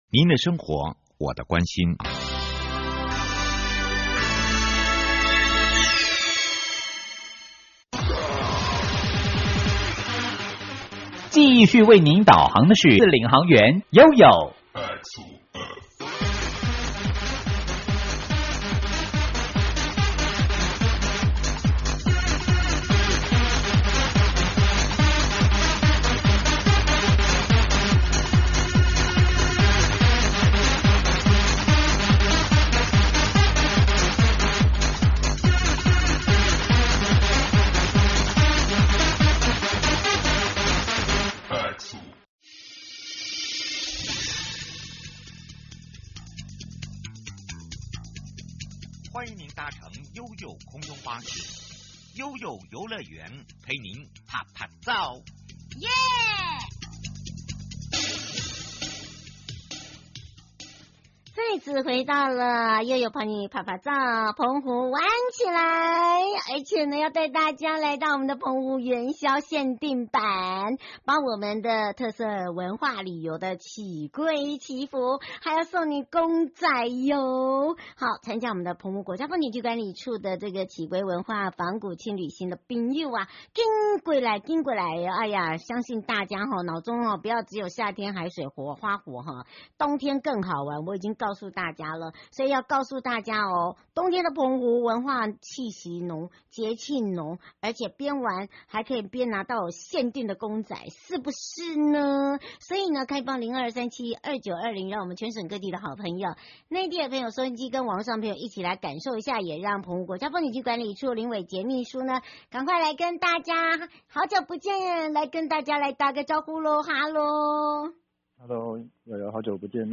在花東縱谷，用料理把時間留住，一間讓你忘記手機的良田工作坊！ 受訪者：